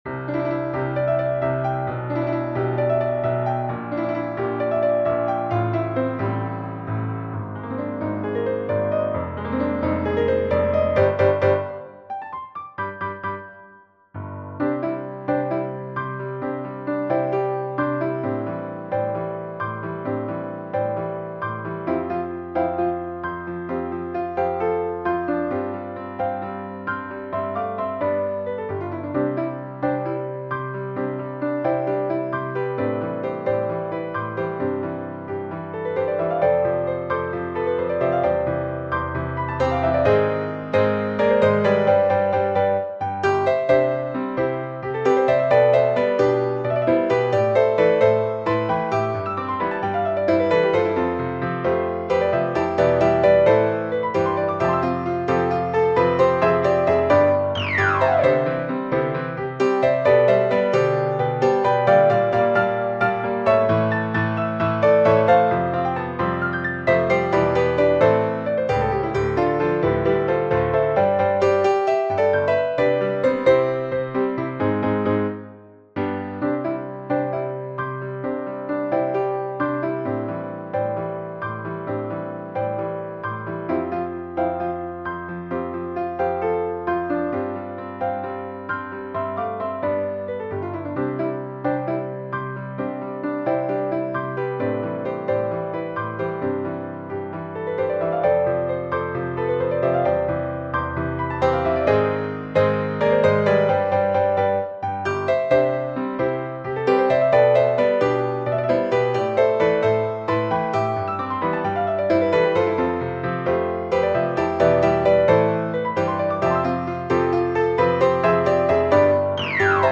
SAB mixed choir and piano